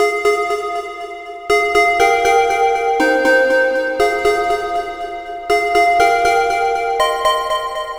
Bell Siren Call.wav